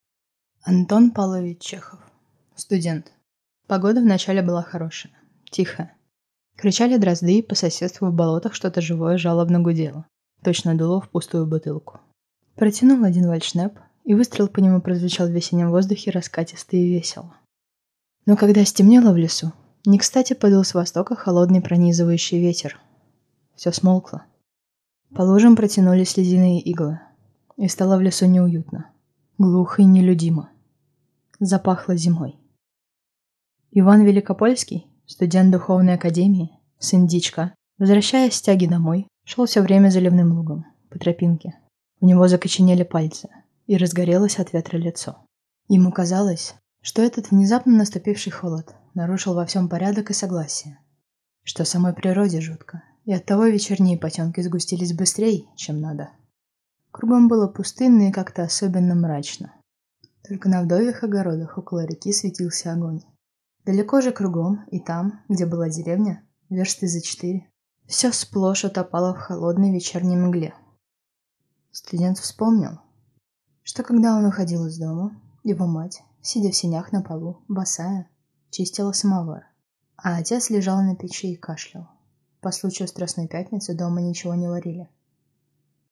Аудиокнига Студент | Библиотека аудиокниг